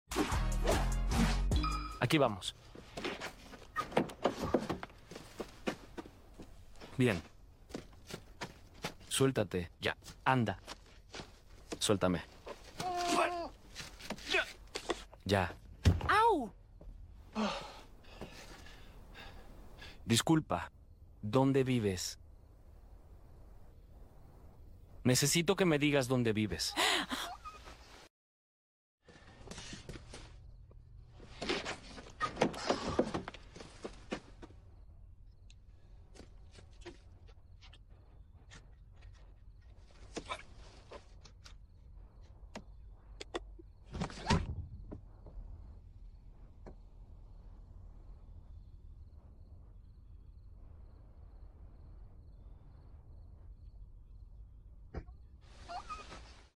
RETO #3 // 🤖 IA vs VOZ HUMANA 🗣 SDV los reta a ponerle voz a un personaje que fue doblado con Inteligencia Artificial 😱🎙